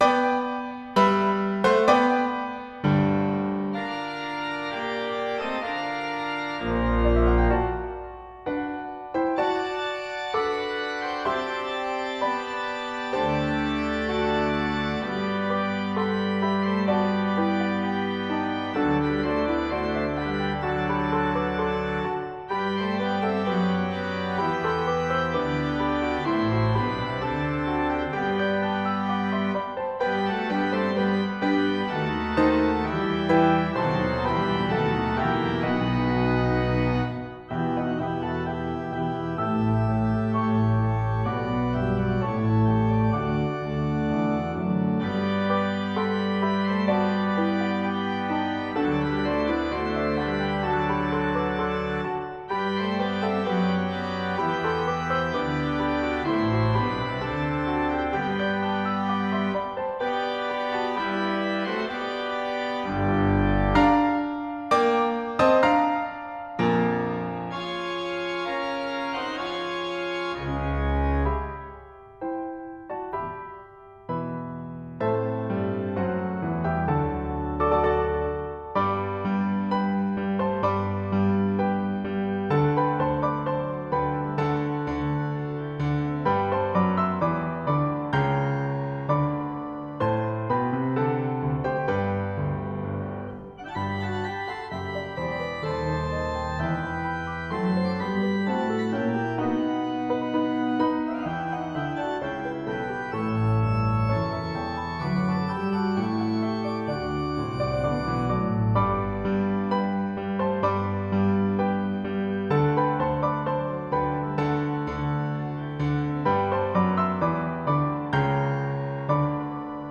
Voicing: Piano and Organ